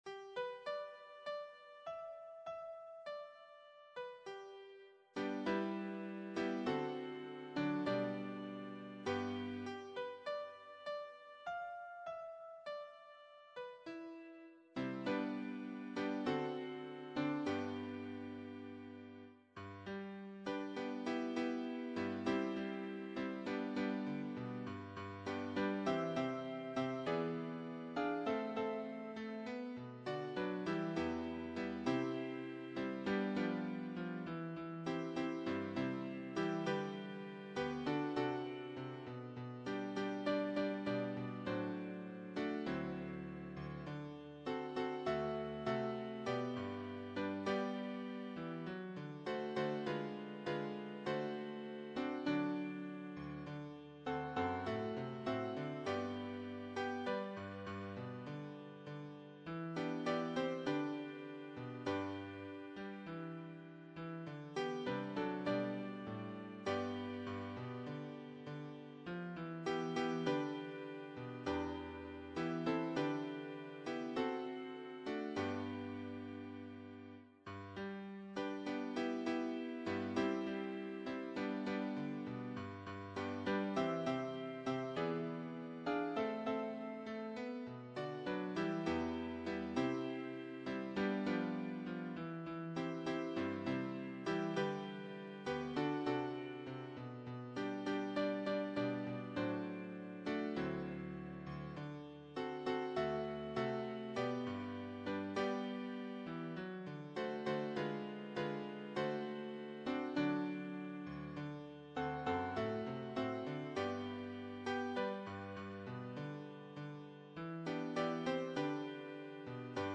choir SATB
Anthem